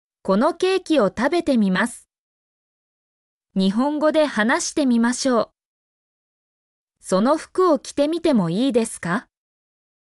mp3-output-ttsfreedotcom-49_jFaF7zH4.mp3